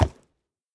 1.21.5 / assets / minecraft / sounds / mob / horse / wood1.ogg
wood1.ogg